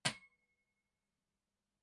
餐具的声音 " 小勺子5
Tag: 餐具